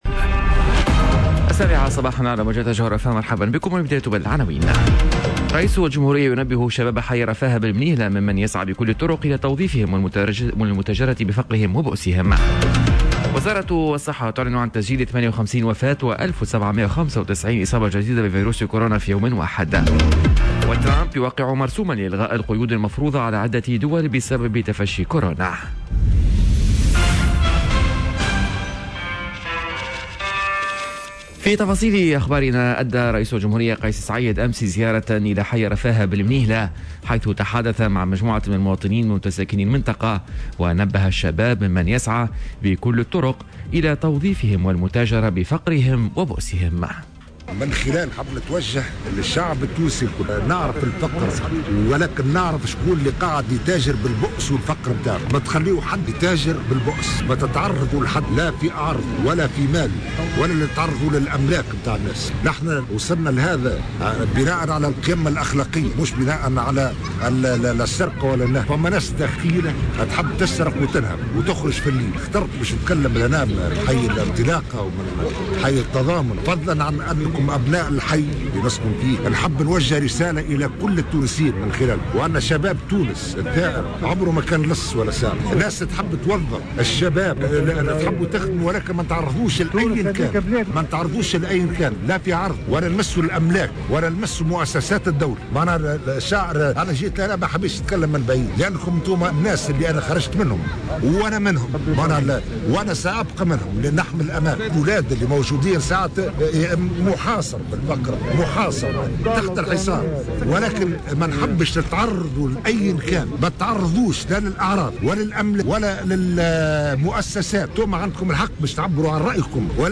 نشرة أخبار السابعة صباحا ليوم الثلاثاء 19 جانفي 2021